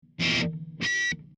guitar hit 1 1 sec. mono 16k
guitarhit1.mp3